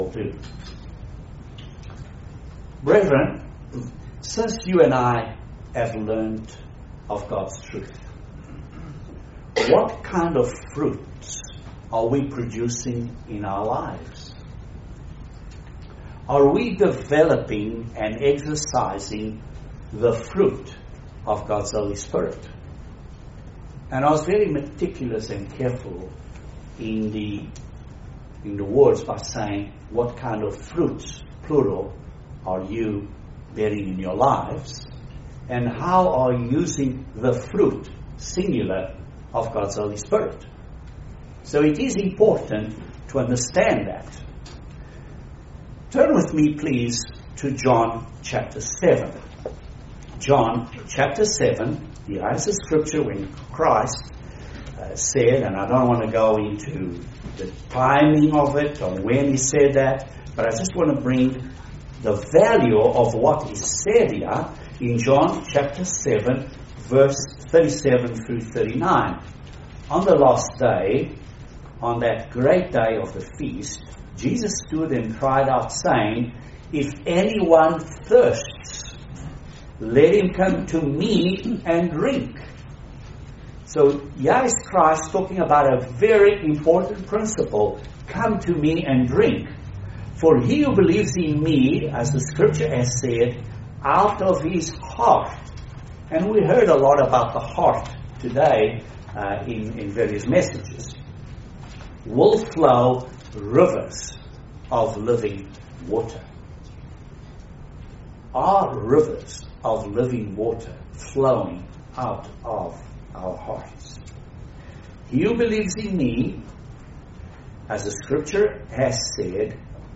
Excellent Sermon on the fruits of the Holy Spirit.
What kinds of fruit are we developing in our lives? Great message for the day of Pentecost.